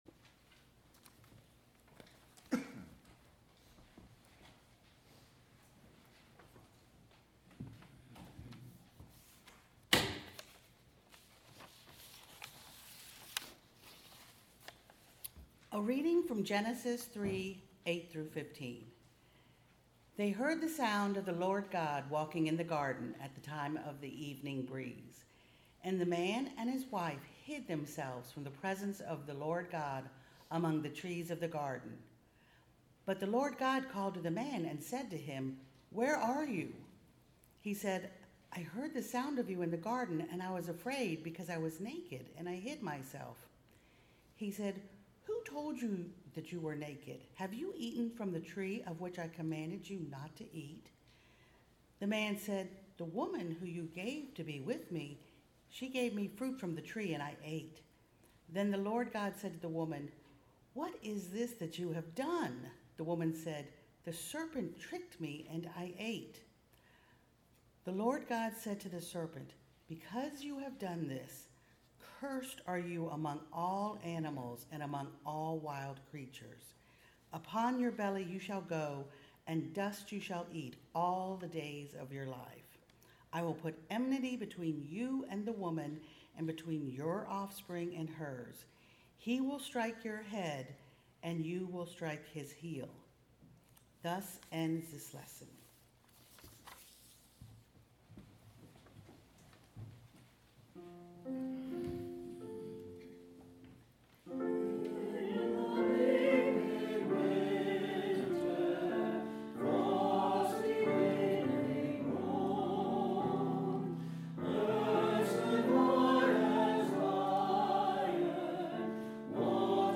This one is all music and reading.
December 14, 2025 – Lessons and Carols